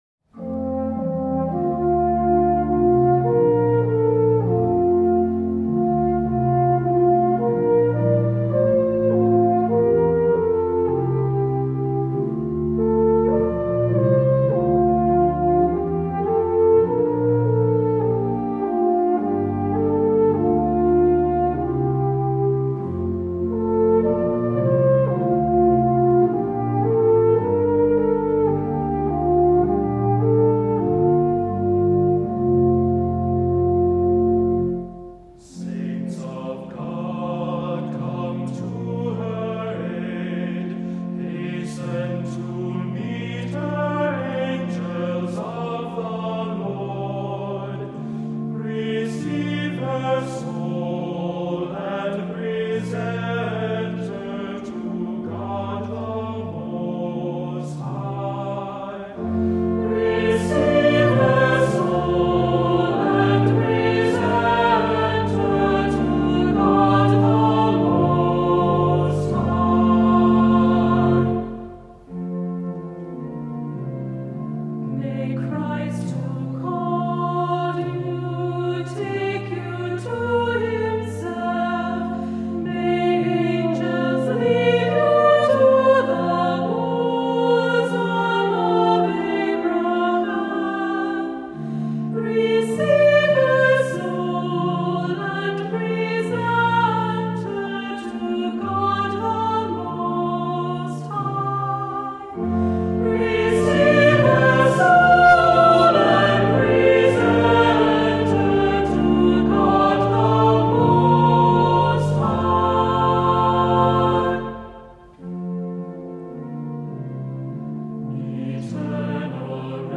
Voicing: Unison,Cantor,Assembly,Descant